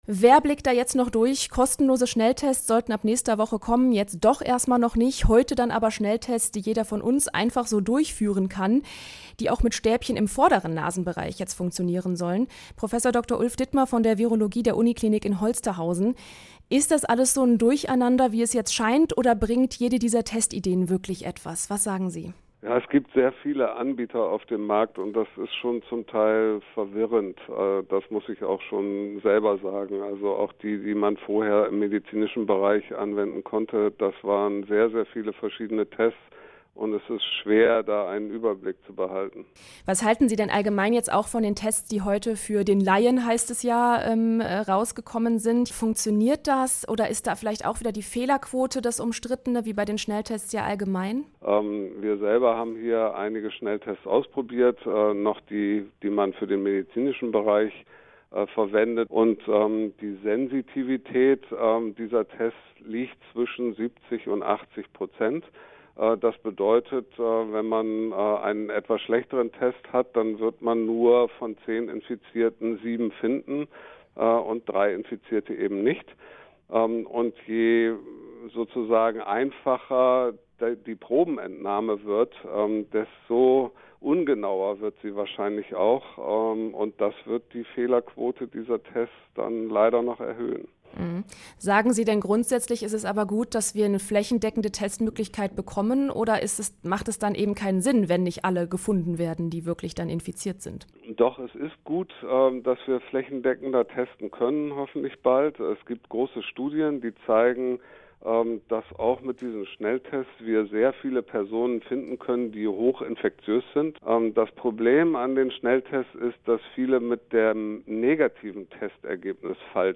ti-schnelltests-virologe.mp3